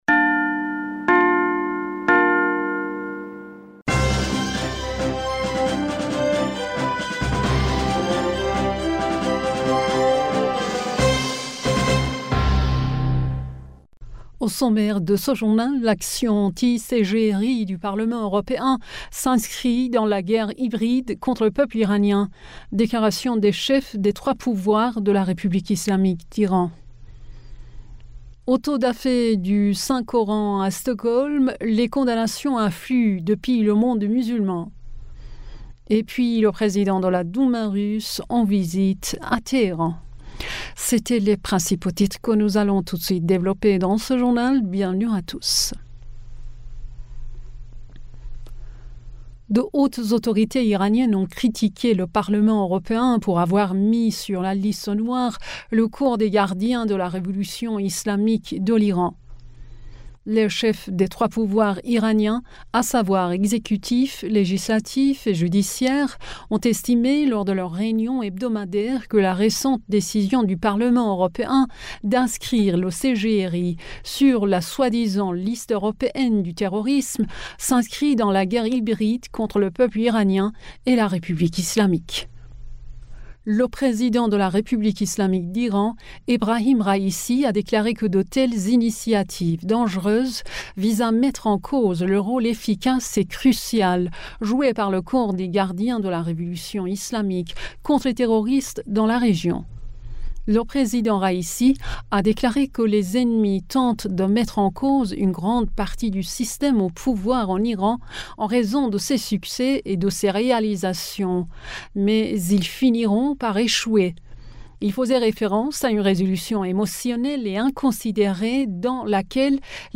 Bulletin d'information du 22 Janvier